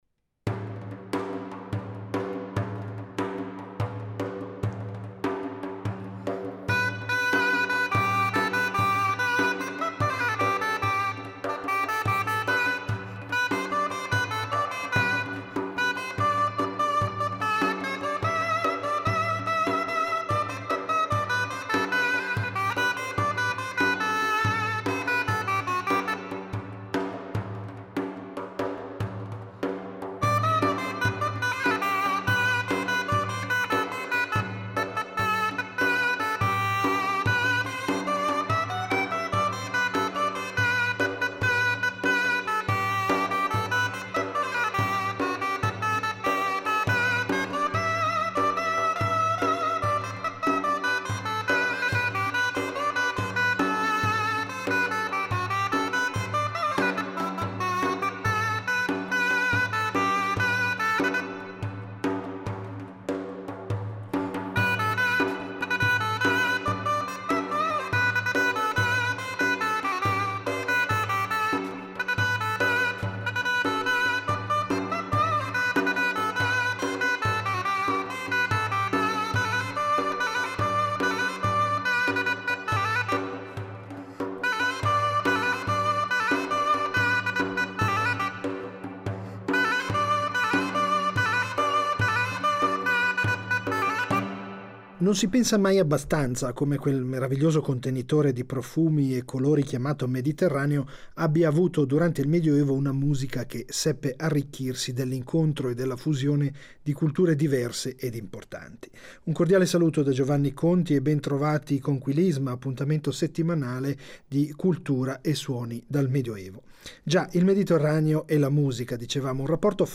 Ciò che ascolteremo rappresenta una parte del discorso e dunque ho scelto un repertorio che potesse sufficientemente esprimere il mondo musicale del XIII secolo dalla Spagna alla Sicilia fino alle terre dei greci e dei turchi. Composizioni profane e sacre che si mescolano nel rispetto delle etnie e dei credo religiosi; facendo scoprire un mondo, impensabile per certuni, in questo secolo di stravolgimenti e di irriverenze etniche e culturali.